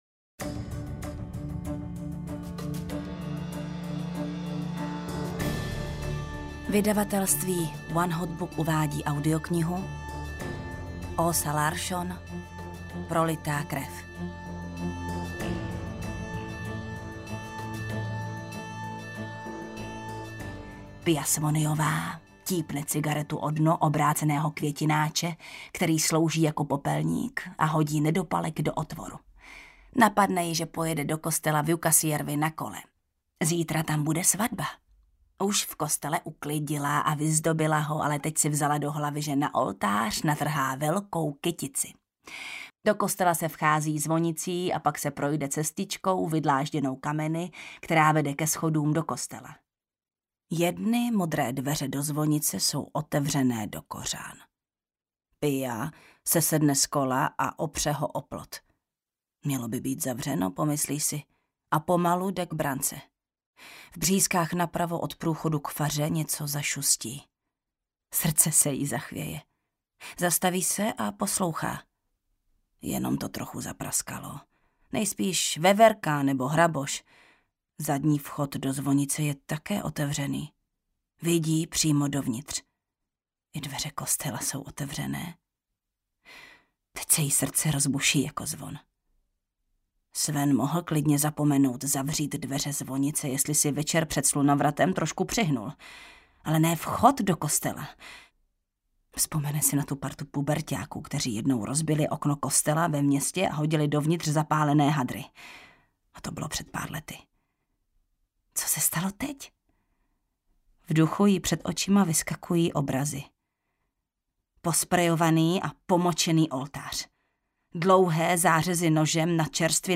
Prolitá krev audiokniha
Ukázka z knihy
• InterpretJana Stryková